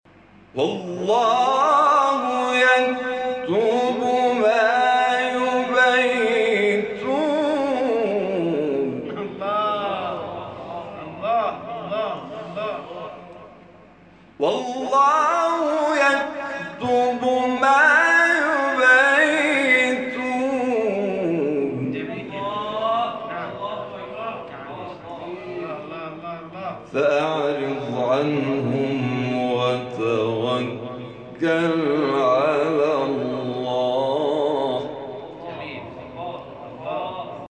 شبکه اجتماعی: فرازهای صوتی از تلاوت قاریان ممتاز کشور را می‌شنوید.